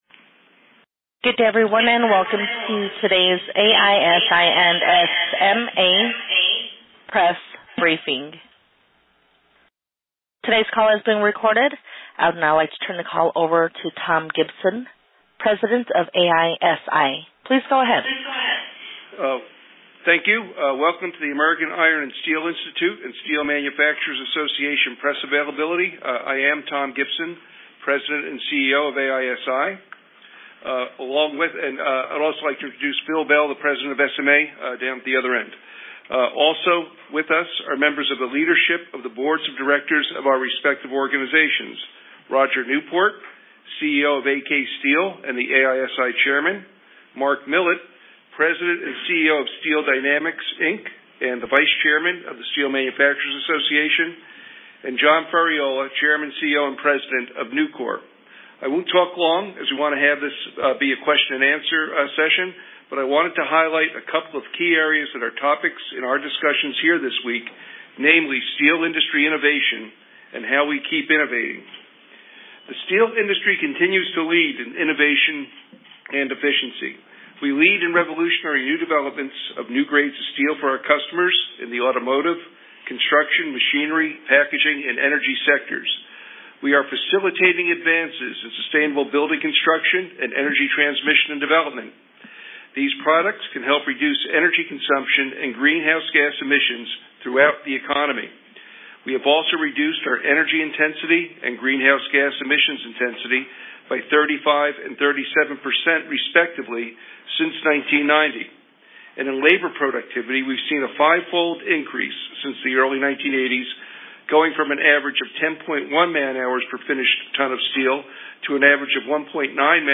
Listen to the full AISI-SMA Joint Press Conference from the 2019 Annual Meeting.